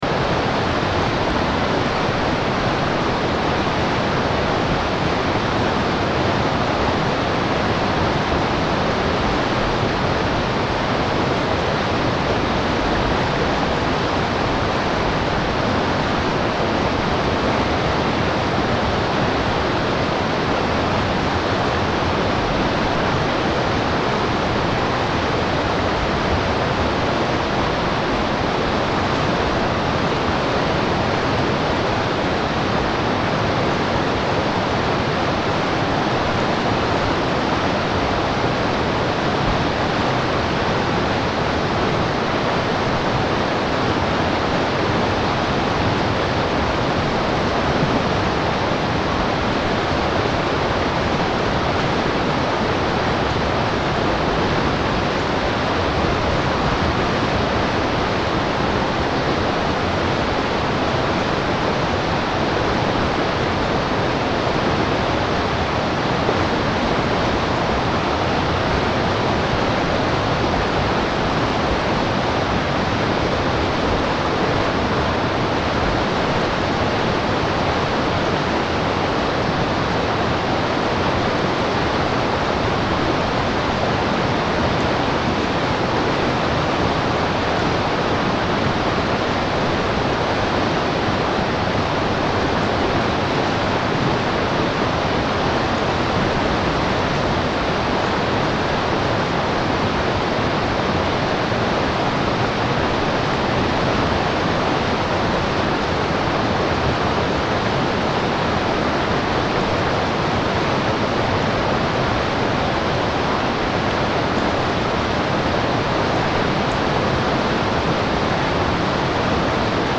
Deep Water Brown Noise For Sleep And Relaxation (Seamless Loop)
The seamless, bass-rich loop masks unwanted noise while mimicking the sound of a powerful, distant waterfall. Immerse yourself in its calming flow and let tension melt away. Noise blocking sound.
Genres: Sound Effects
Deep-water-brown-noise-for-sleep-and-relaxation-seamless-loop.mp3